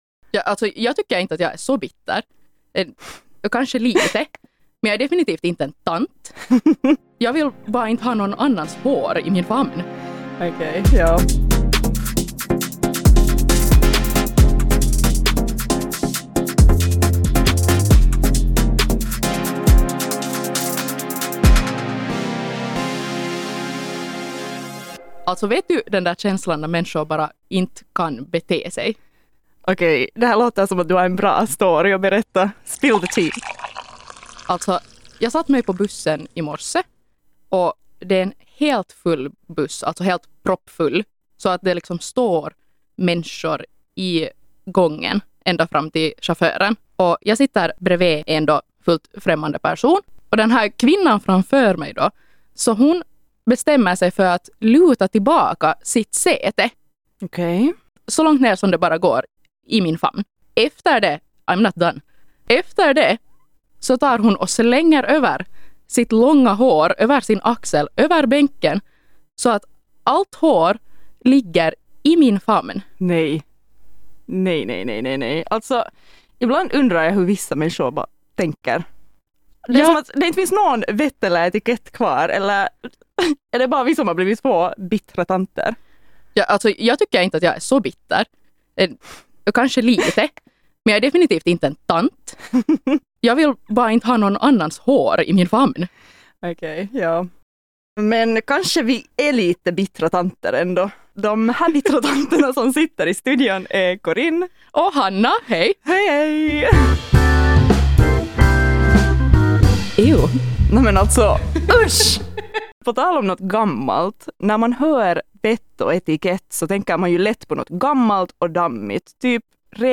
Resultatet? Skratt, sanningar och en hel del tveksamma etikettsregler.